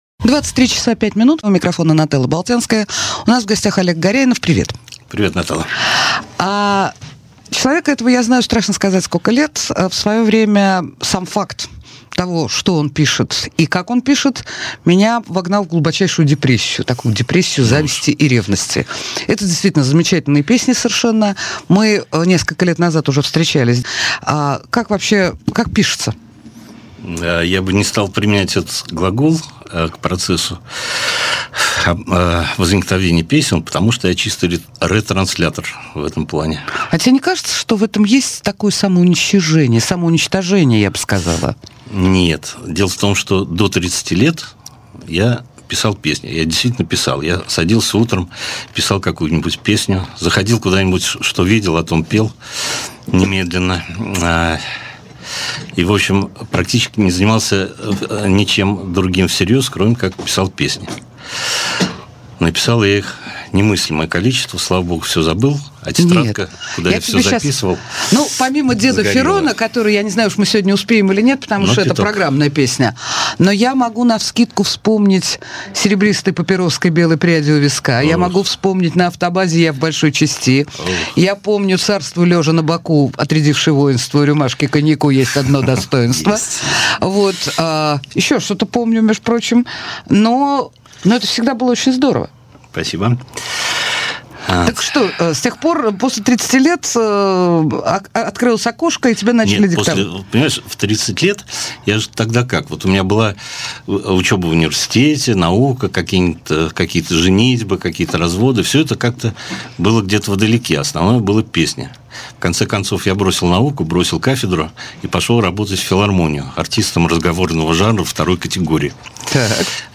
российский бард
Жанр: Авторская песня